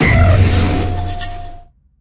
Cri d'Ire-Foudre dans Pokémon HOME.